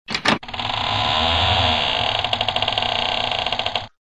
Звуки хлопанья дверью
8. Скрип двери при открытии